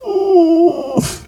pgs/Assets/Audio/Animal_Impersonations/bear_pain_whimper_15.wav at master
bear_pain_whimper_15.wav